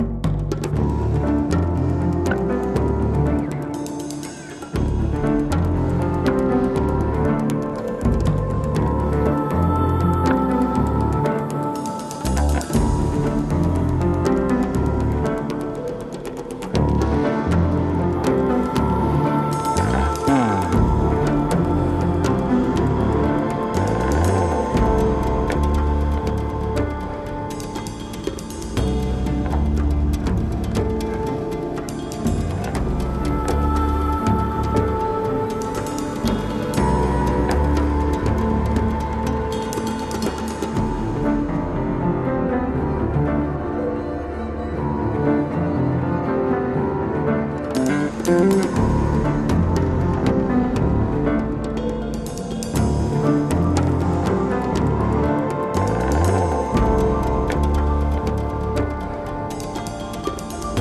a cool, jazzy trip with a sci-fi chaser